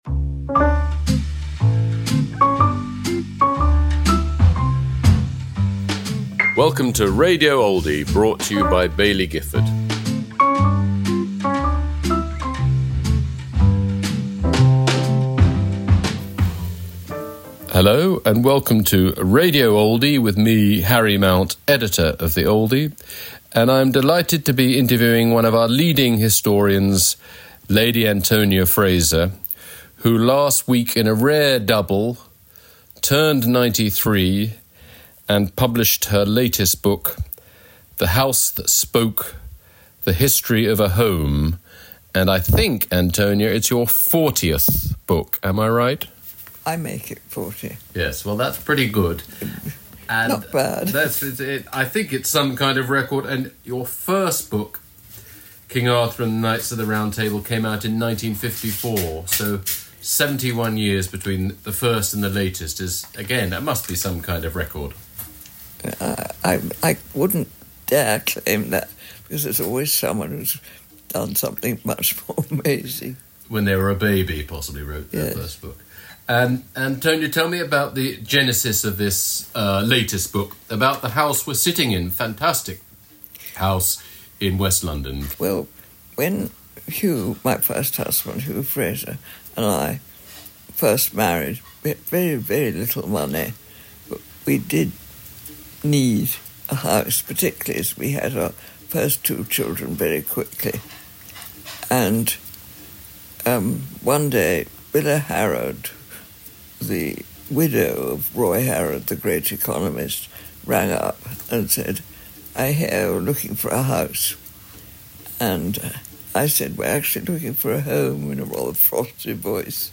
Harry Mount in conversation with Antonia Fraser